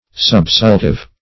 Subsultive \Sub*sul"tive\, a.
subsultive.mp3